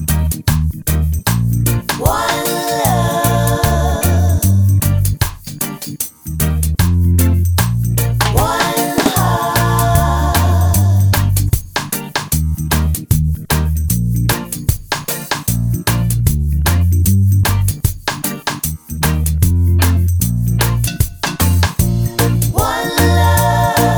no Backing Vocals Reggae 3:03 Buy £1.50